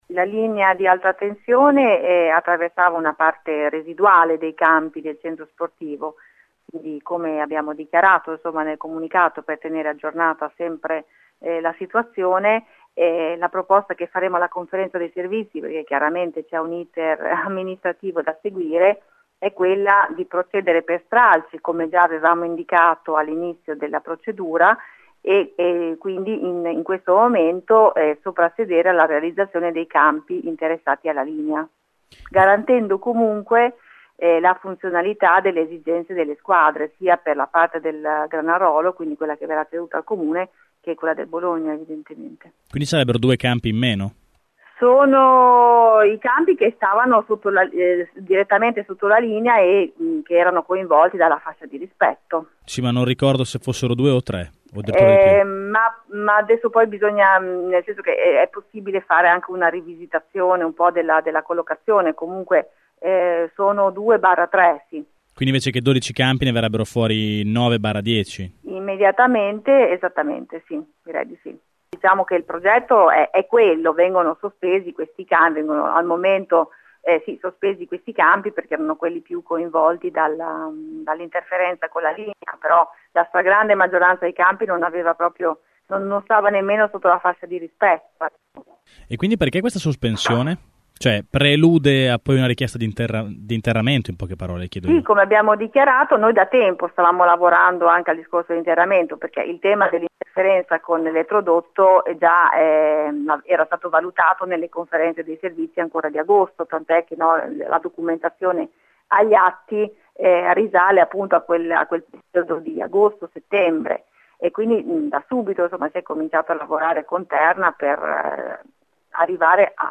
Ascolta il sindaco di Granarolo Loretta Lambertini